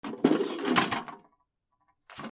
ちりとり 物音 金属
/ M｜他分類 / L05 ｜家具・収納・設備
『ゴソ ゴト』